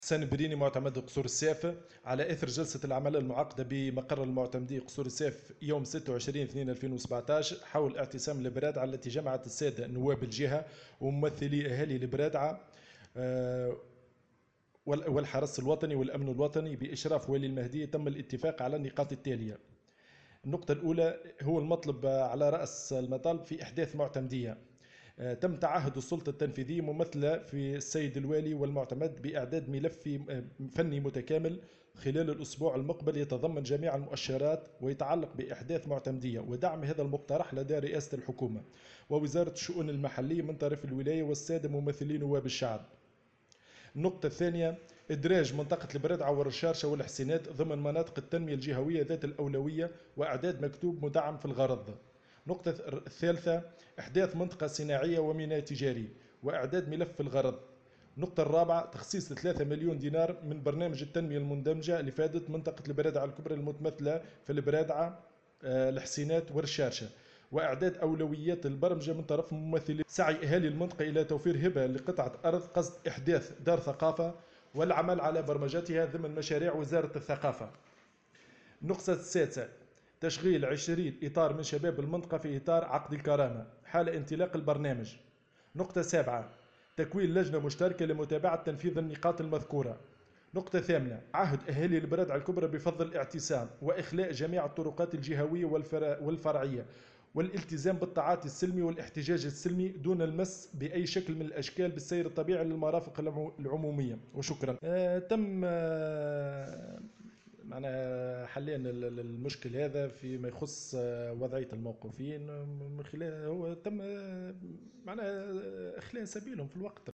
وأكد معتمد قصور الساف حسان البريني في تصريح لمراسل الجوهرة "اف ام" أن السلطة التنفيدية ممثلة في الوالي والمعتمد تعهدوا بإعداد ملف فني متكامل الأسبوع المقبل حول مطلب احداث معتمدية يتضمن جميع المؤشرات ويتعلق بإحداثها ودعم هذا المقترح لدى رئاسة الحكومة ووزارة الشؤون المحلية من طرف الولاية وممثلي الشعب.